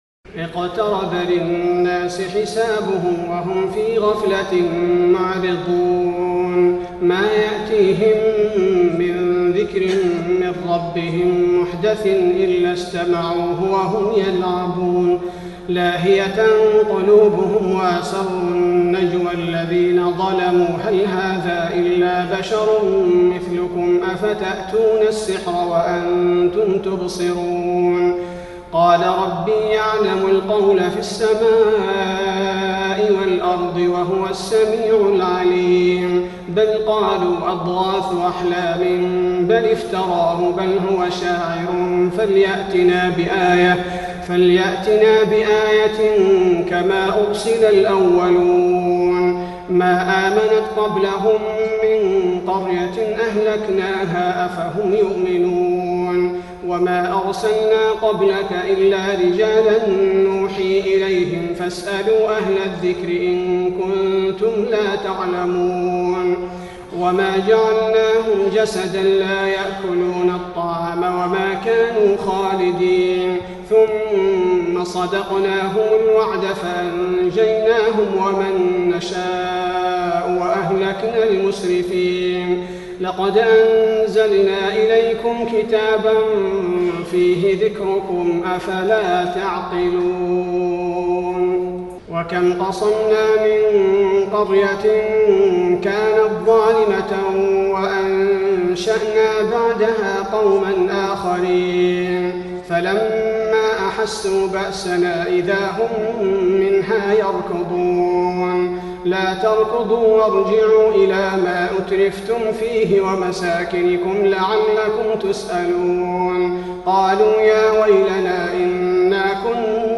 تراويح الليلة السادسة عشر رمضان 1425هـ سورة الأنبياء كاملة Taraweeh 16 st night Ramadan 1425H from Surah Al-Anbiyaa > تراويح الحرم النبوي عام 1425 🕌 > التراويح - تلاوات الحرمين